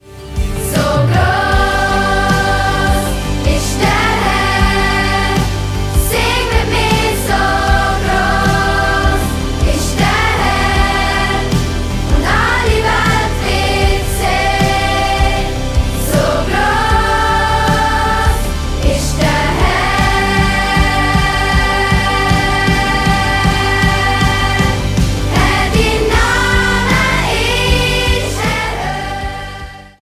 Mundartworship für Kids und Preetens